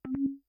sound_click.ogg